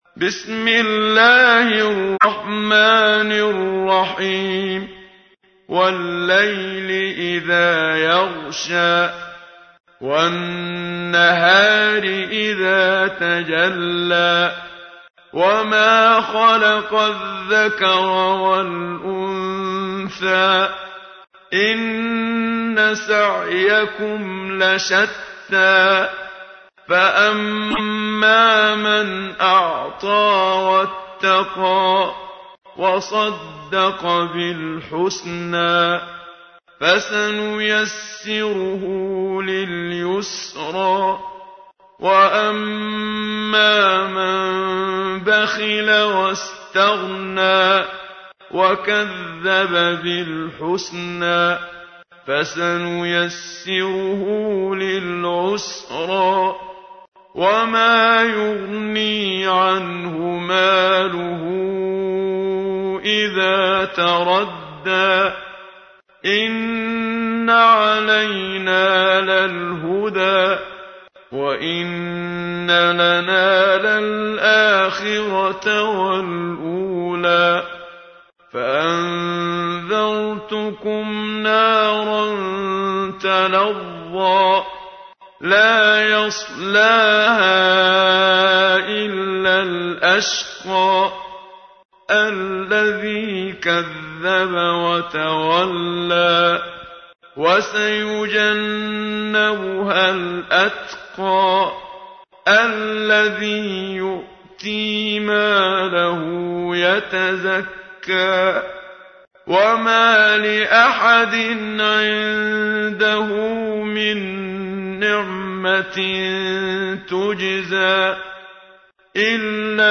تحميل : 92. سورة الليل / القارئ محمد صديق المنشاوي / القرآن الكريم / موقع يا حسين